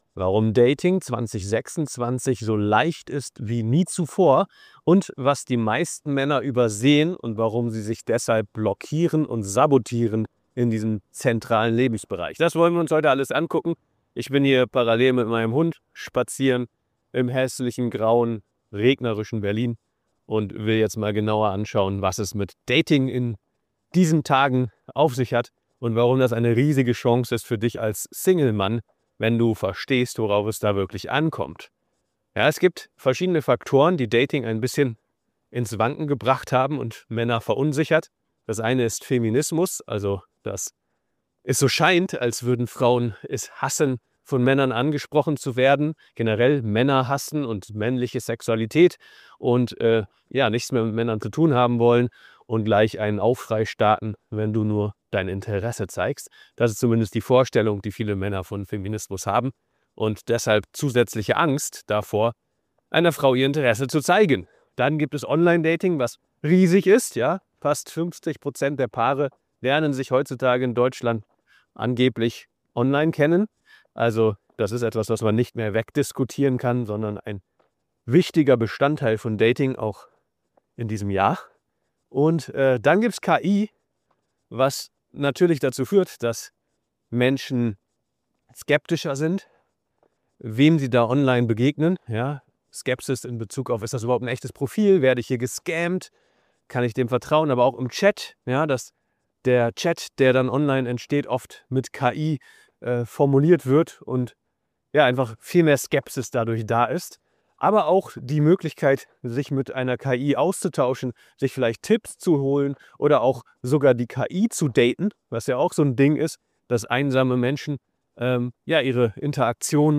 Spaziergang durch Berlin und wir schauen uns gemeinsam an, warum